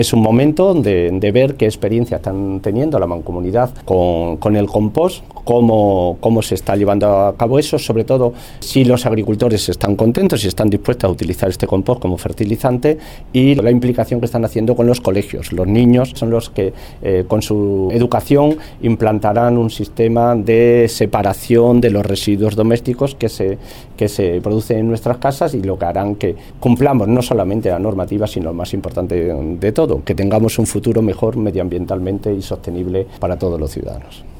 El viceconsejero de Medio Ambiente, Agapito Portillo, habla del proyecto Henares Agrocomposta.